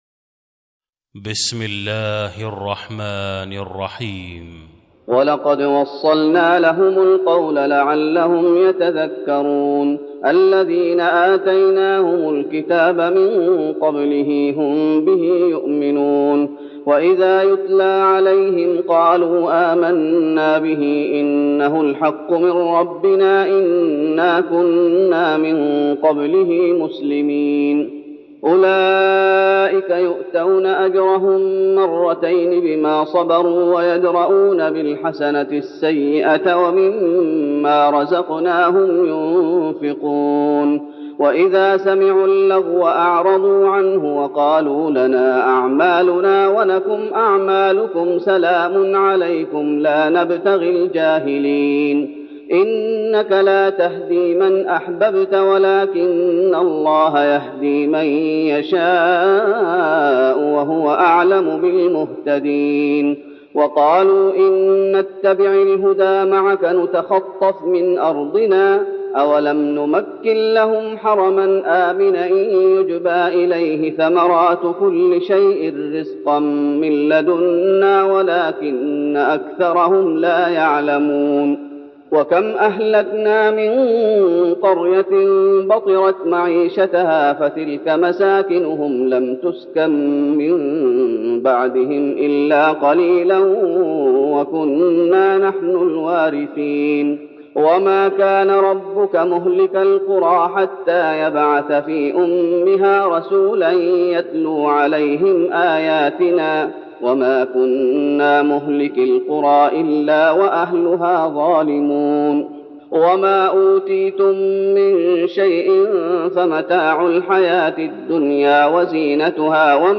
تراويح رمضان 1412هـ من سورة القصص (51-88) Taraweeh Ramadan 1412H from Surah Al-Qasas > تراويح الشيخ محمد أيوب بالنبوي 1412 🕌 > التراويح - تلاوات الحرمين